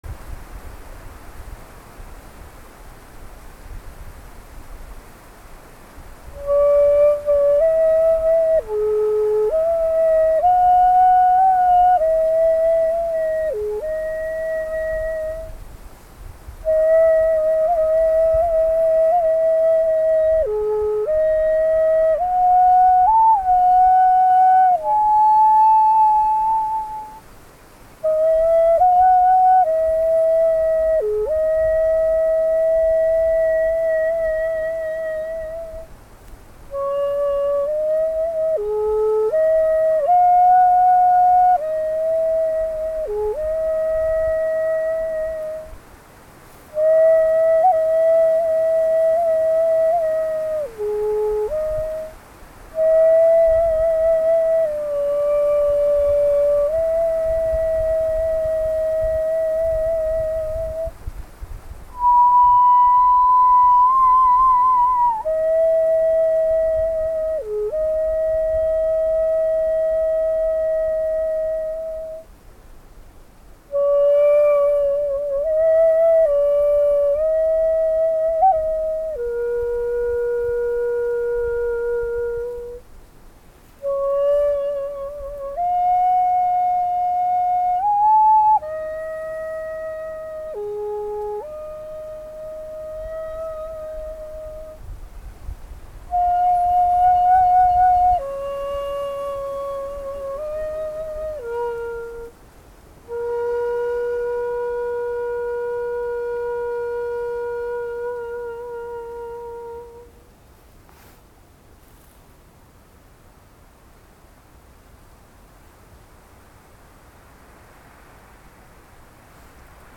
新緑･山伏・一節切(ひとよぎり）
途中、青葉若葉のまぶしい中、持ってきた一節切（ひとよぎり）を吹きました。
演奏の合間、風が木々を渡っていく音もしますので、それもお聞きください。